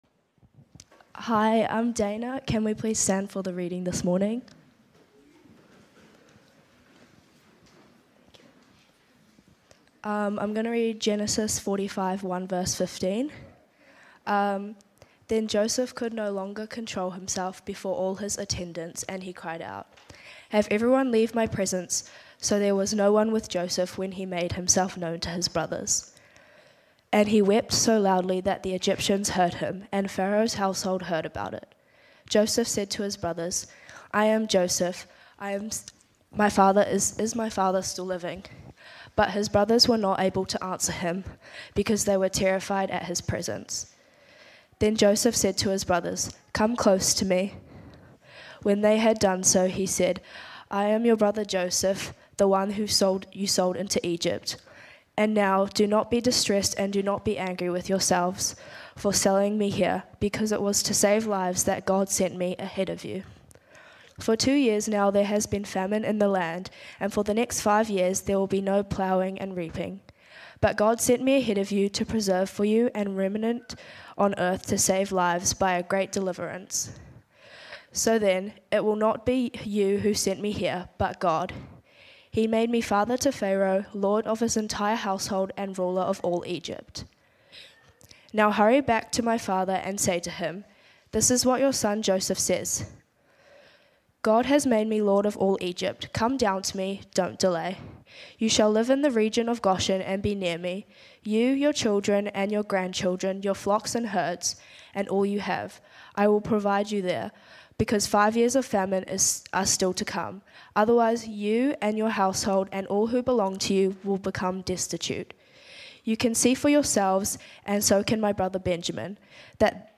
Sermons | Titirangi Baptist Church
Although we're continuing our series in Genesis, looking at the story of Joseph the Dreamer, it is our Youth who are leading us. Today we're looking at reconciliation as revealed through the story of Joseph.